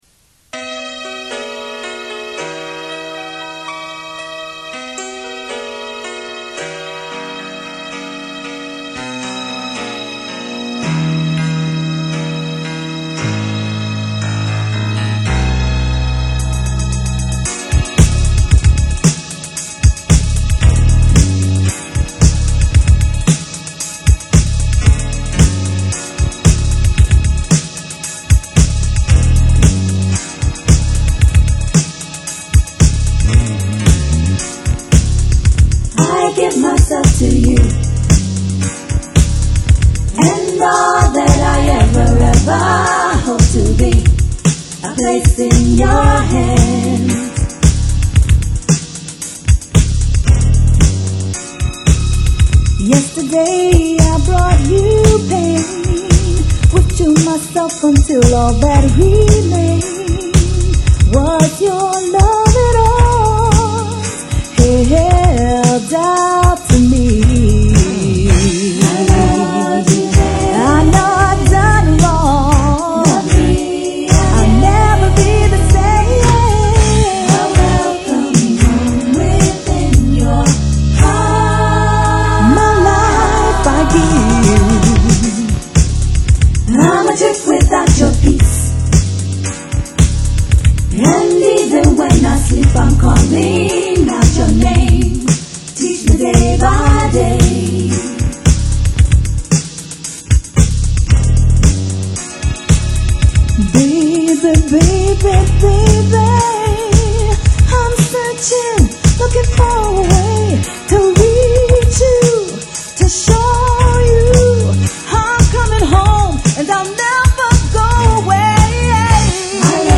sweeten up the backgrounds